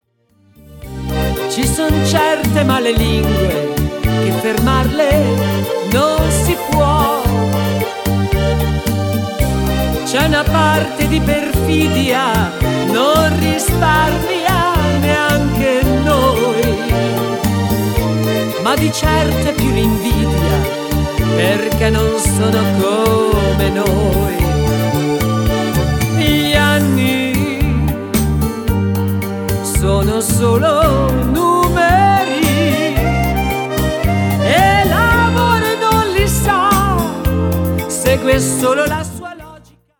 BEGUINE  (3.57)